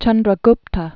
(chŭndrə-gptə) Died c. 297 BC.